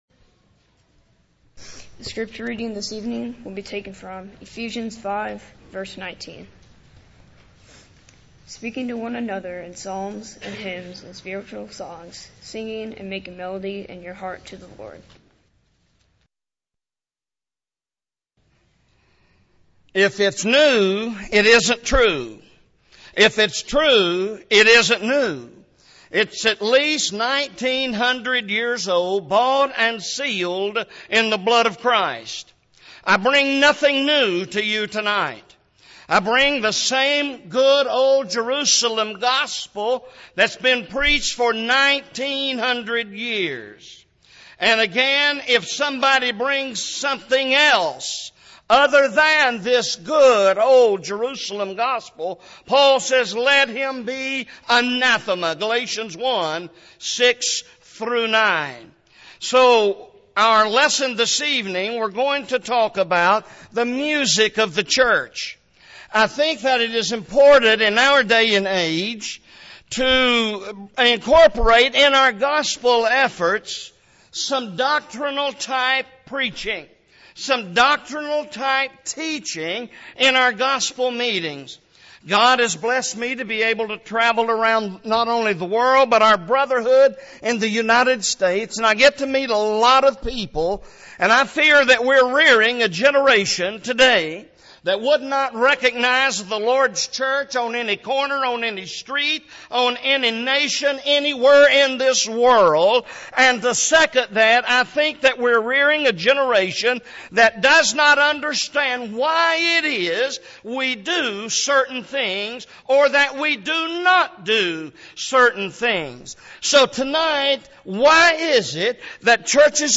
Service Type: Gospel Meeting